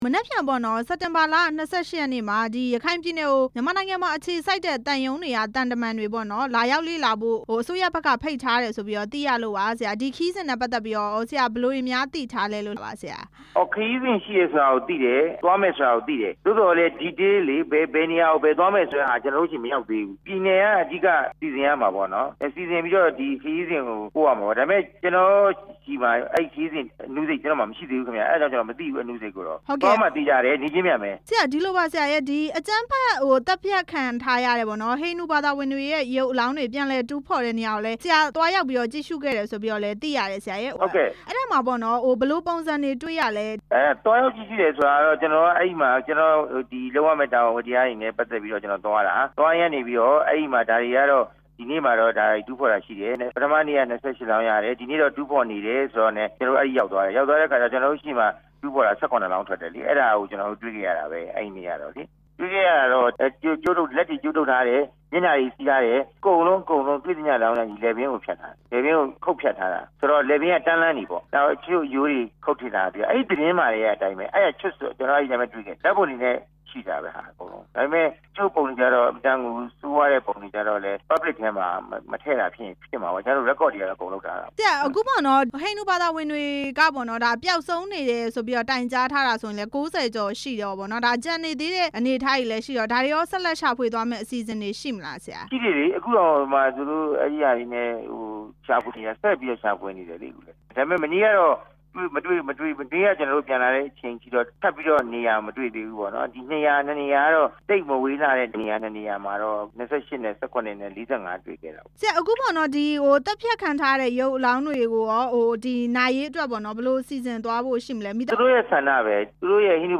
ရခိုင်ပြည်နယ်အရေး ဆောင်ရွက်မှု မေးမြန်းချက်